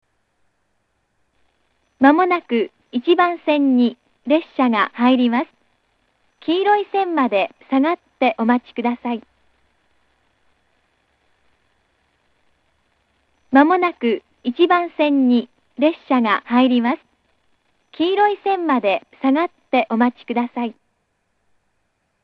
車掌がスイッチを押すと、自動放送のあとに一定時間ベルが流れます。
接近放送